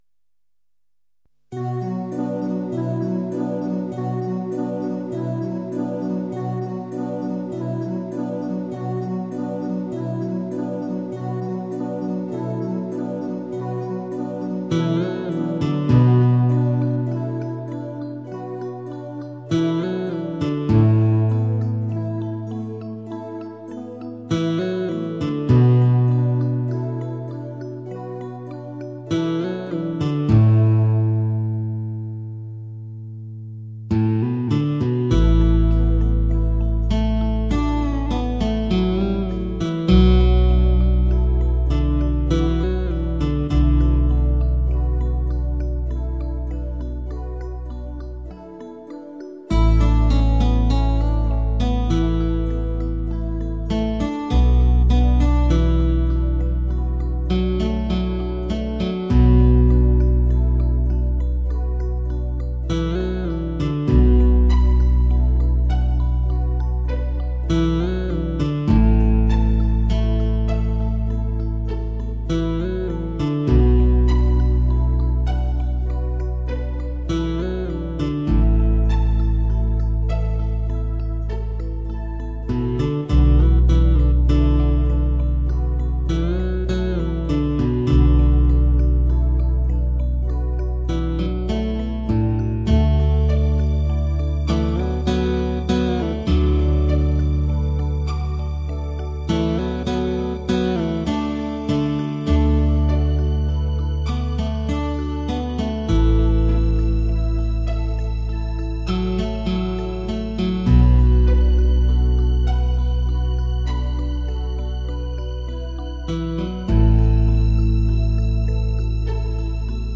琵琶
笛子、巴乌、洞箫